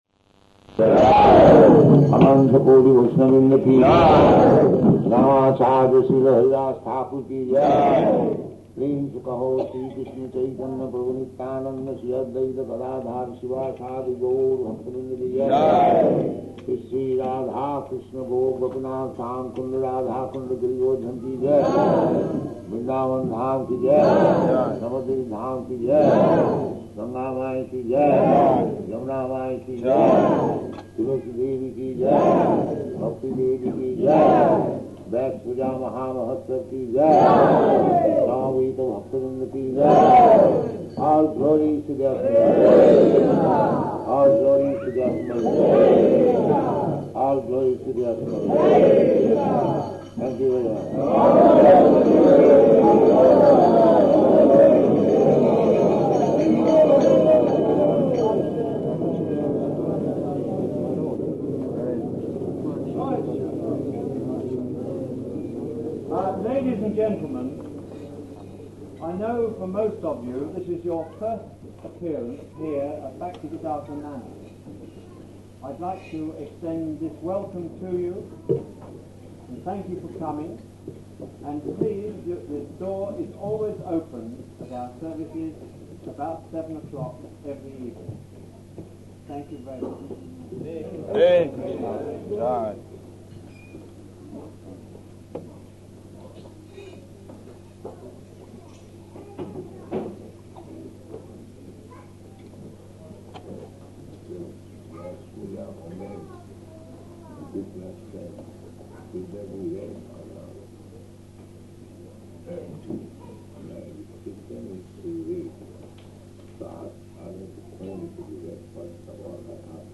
Lecture, "What is a Guru?"